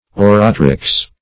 Search Result for " oratrix" : The Collaborative International Dictionary of English v.0.48: Oratrix \Or"a*trix\, n. [L.]